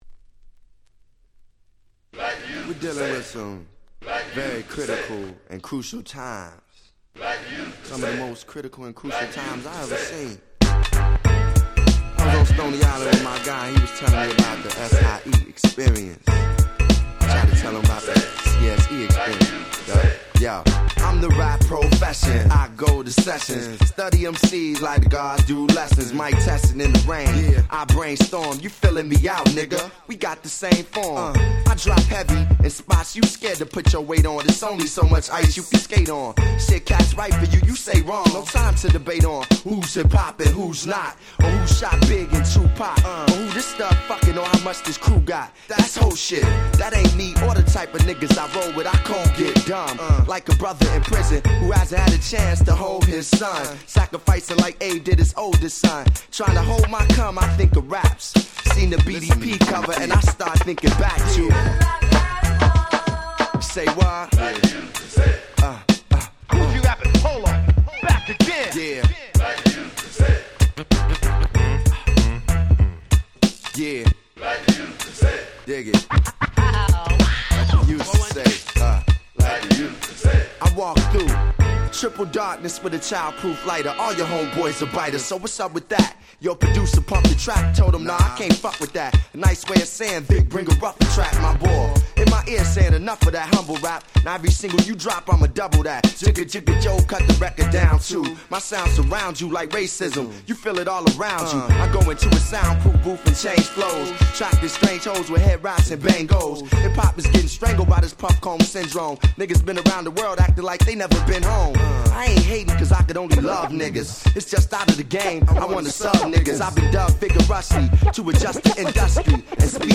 99' Smash Hit Hip Hop !!
90's Boom Bap ブーンバップ